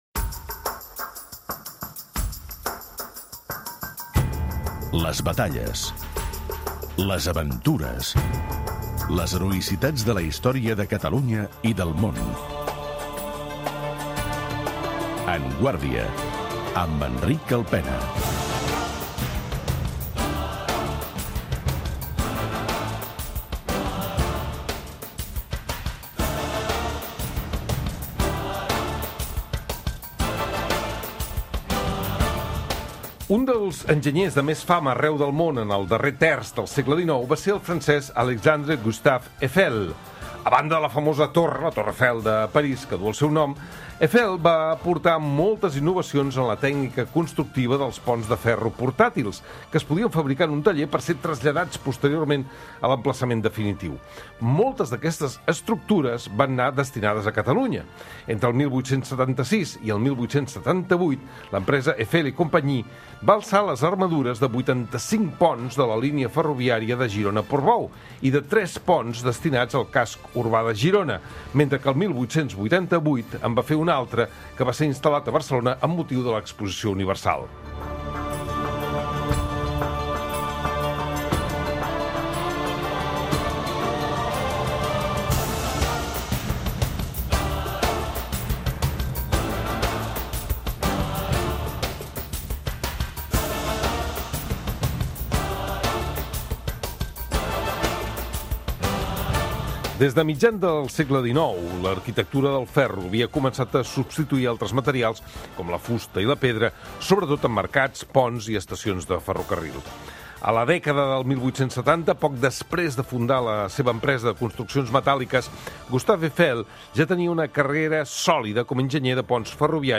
historiador